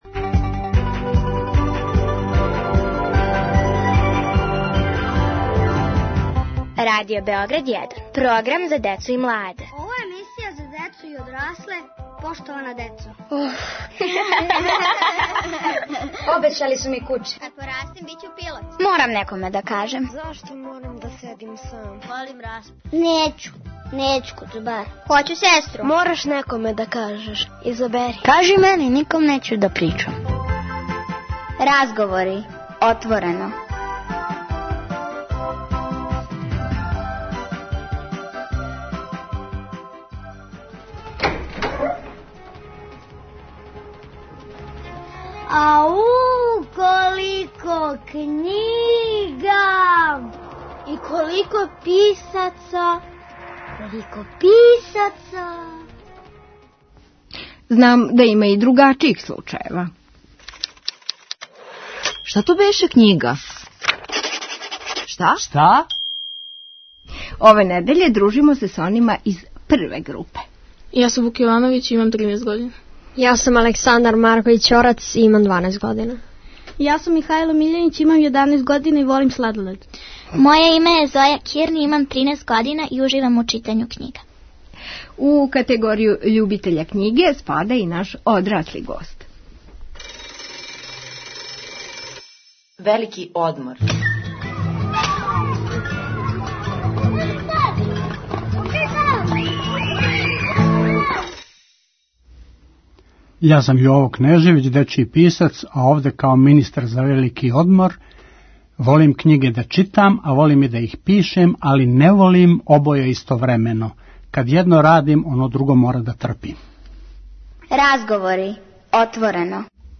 Разговори - отворено. Поштована деца отворено разговарају о својим омиљеним књигама и понекој која их није баш привукла.